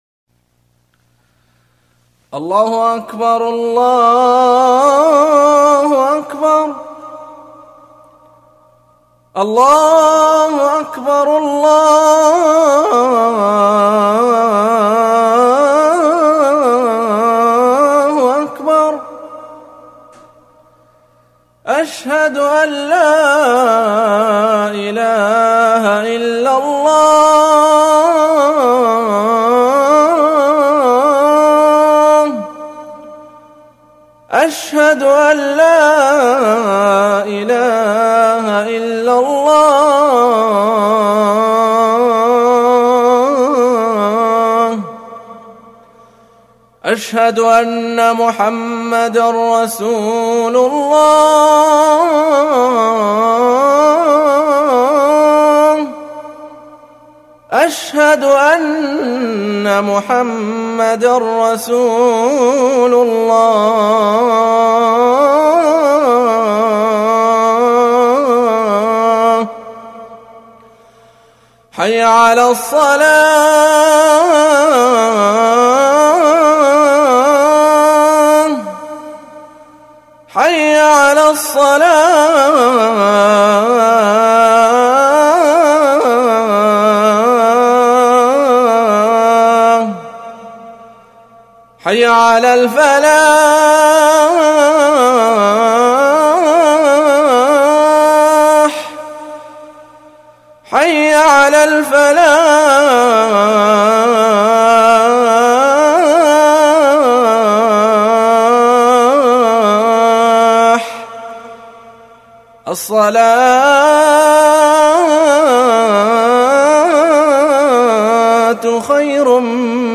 آذان
المكتبة الصوتية روائع الآذان المادة آذان